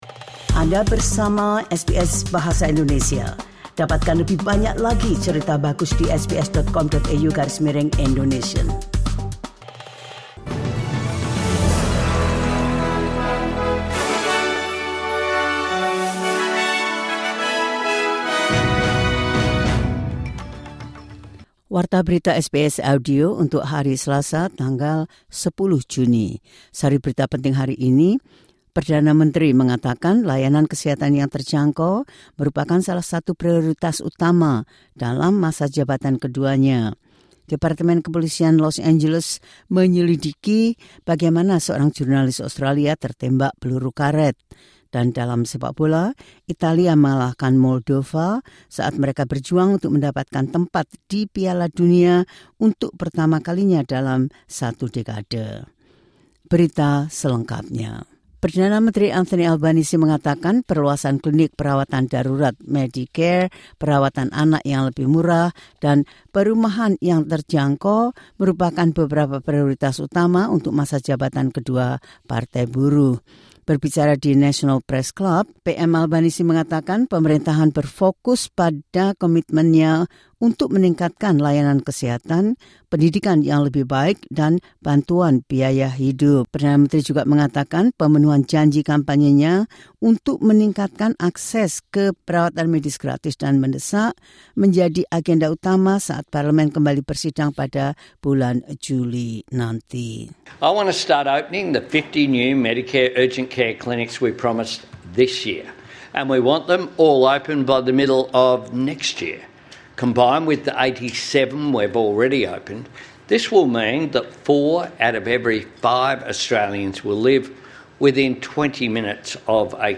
Berita terkini SBS Audio Program Bahasa Indonesia – 10 Jun 2025
The latest news SBS Audio Indonesian Program – 10 Jun 2025.